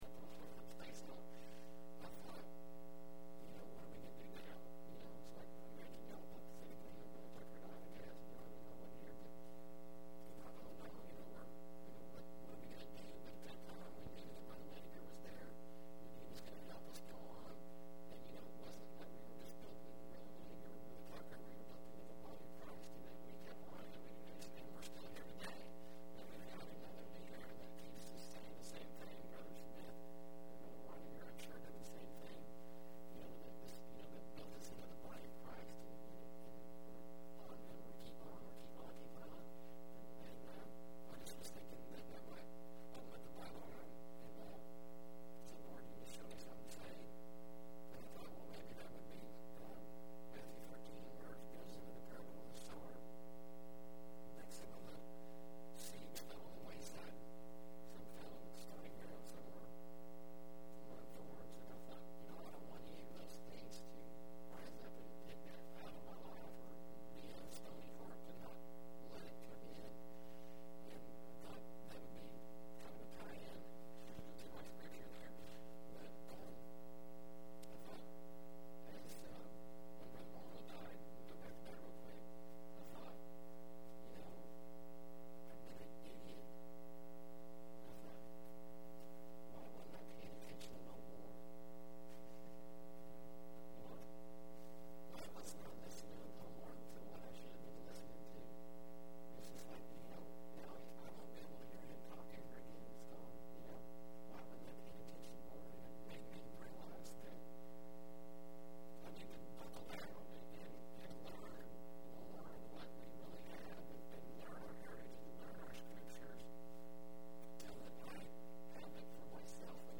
06/23/10 Wednesday Evening
Wednesday Evening Service: 100623_1A.mp3 , 100623_1B.mp3